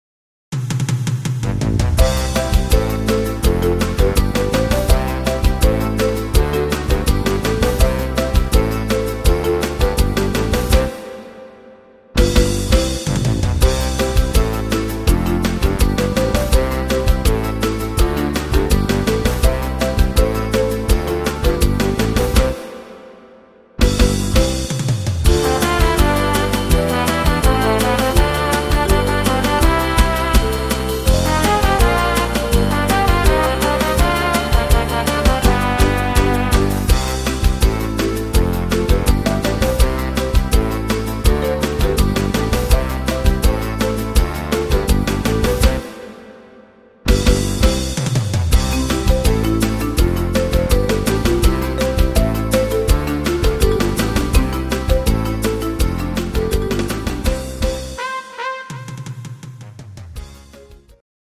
instr. Trompeten